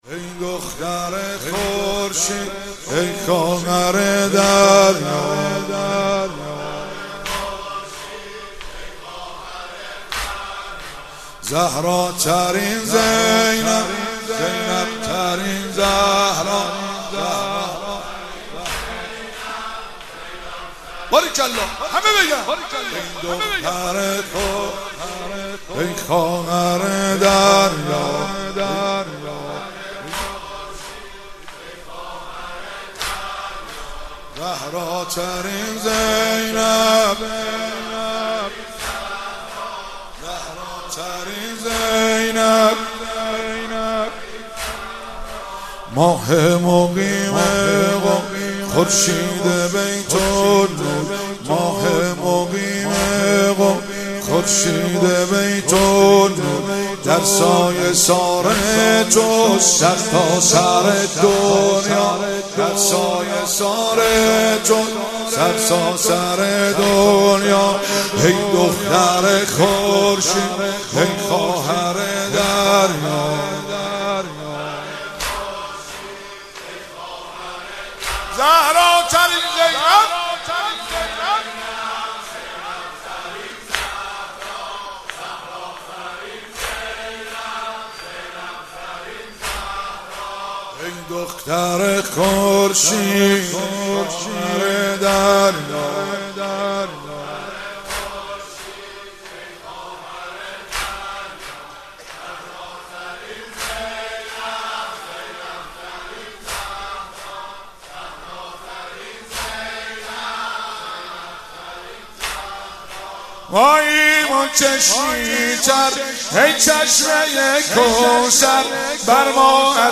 واحد (جلسه هفتگی، ۱۴آذر